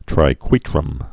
(trī-kwētrəm, -kwĕtrəm)